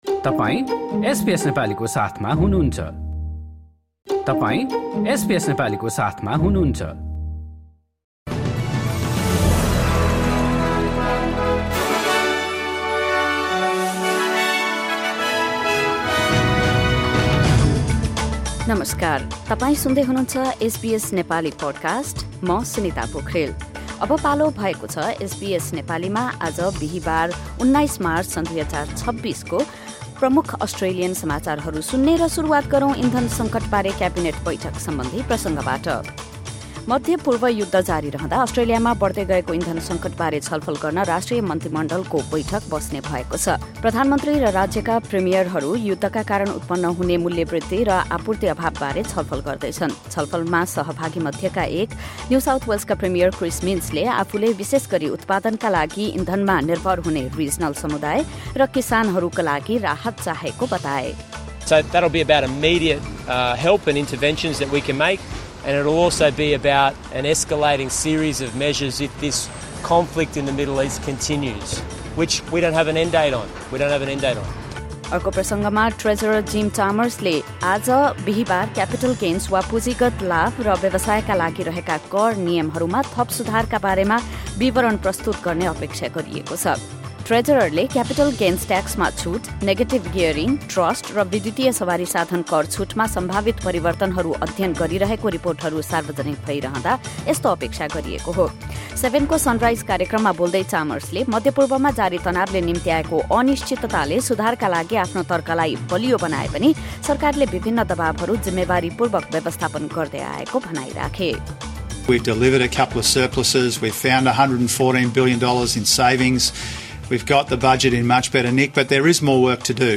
SBS Nepali Australian News Headlines: Thursday, 19 March 2026